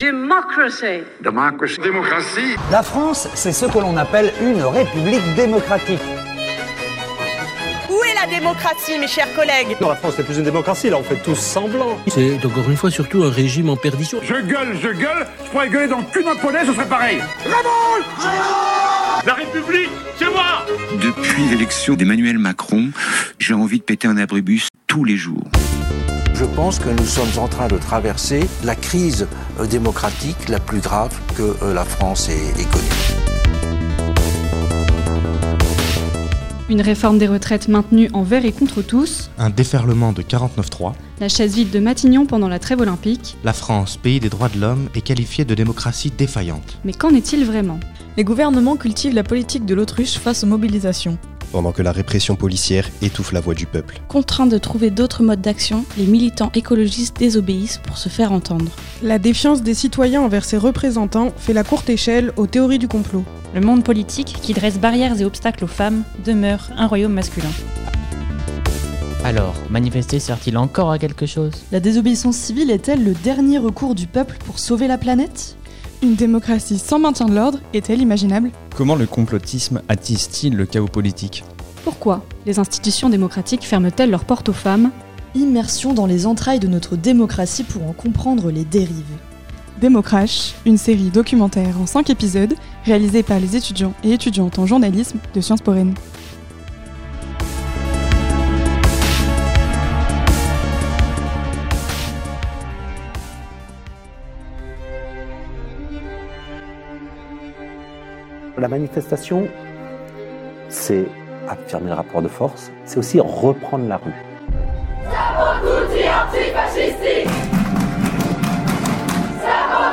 Emission spéciale | Démocrash - Episode 1 : La rue est à bout